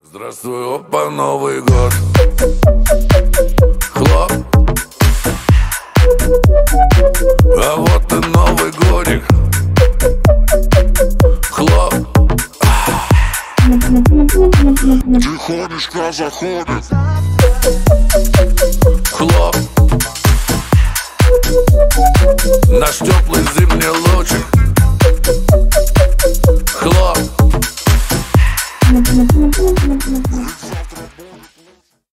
Поп Музыка
новогодние
клубные